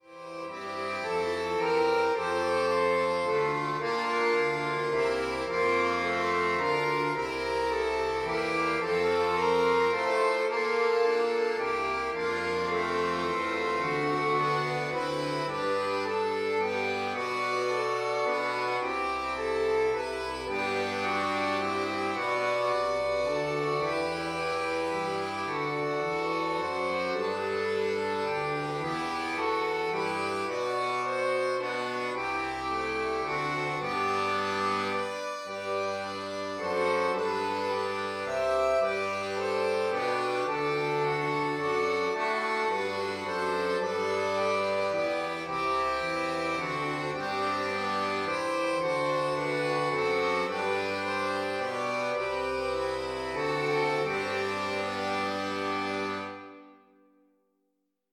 Traditionelles Weihnachtslied
neu arrangiert für Akkordeon-Duo
Christmas Carol